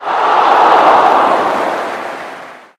snd_audience_dis.ogg